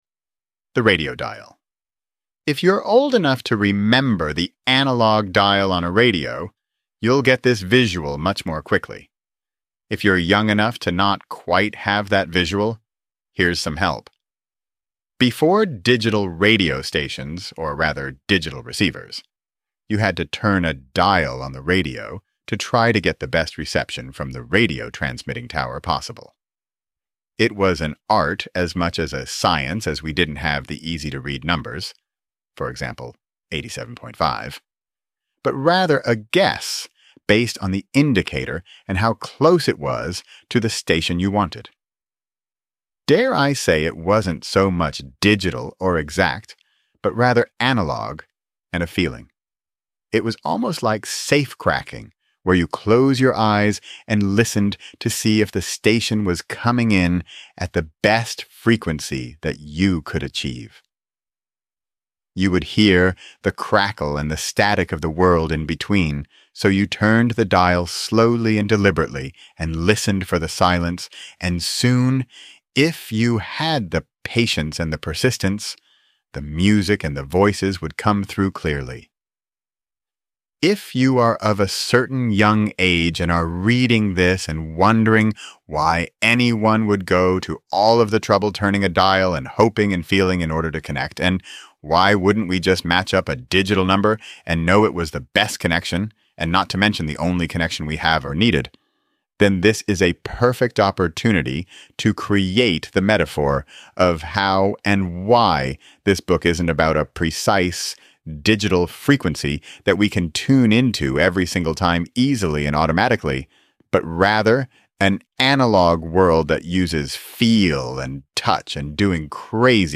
This is a synthesization of my voice.